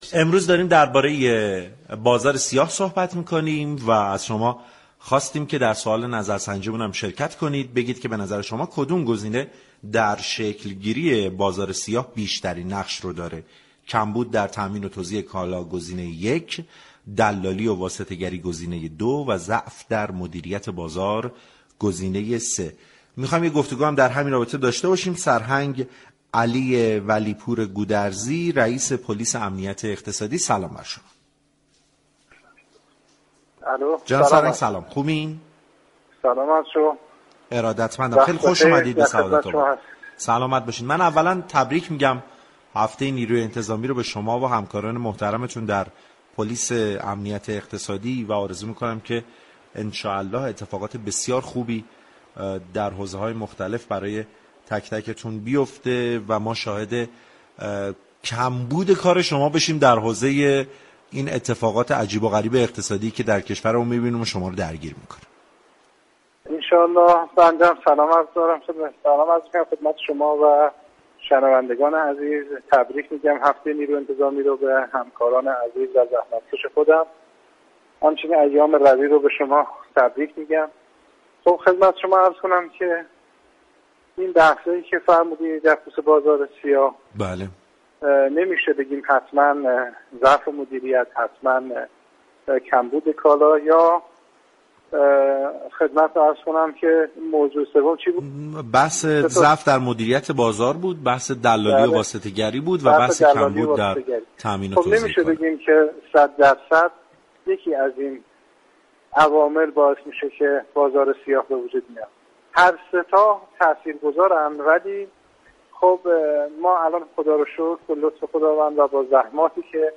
به گزارش پایگاه اطلاع رسانی رادیو تهران، سرهنگ علی ولی‌پور گودرزی رئیس پلیس امنیت اقتصادی تهران بزرگ در گفتگو با برنامه سعادت‌آباد رادیو تهران در پاسخ به این پرسش كه از دیدگاه شما كدام یك از این موارد، كمبود در تامین و توزیع كالا، دلالی و واسطه‌گری و ضعف در مدیریت بازار در شكل‌گیری بازار سیاه بیشترین نقش را دارد؟